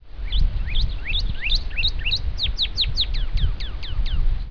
Birds:
cardinal.wav